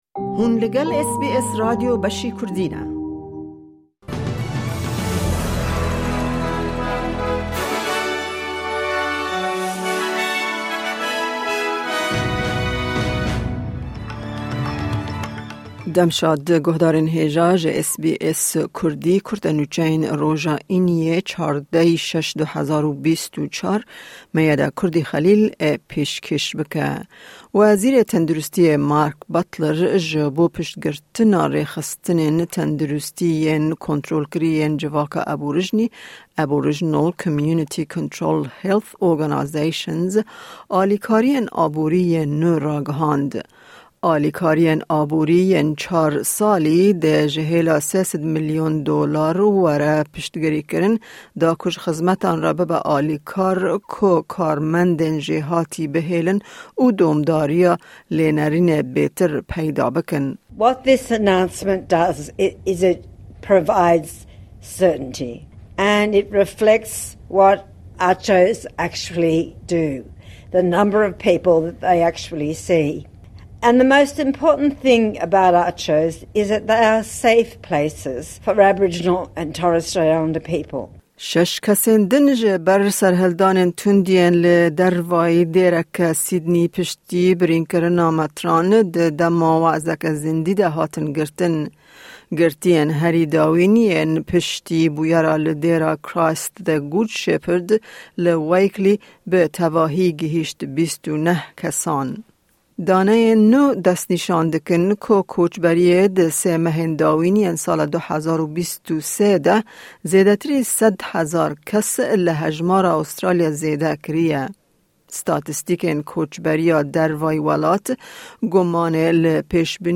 Kurte Nûçeyên roja Înî 14î Hezîrana 2024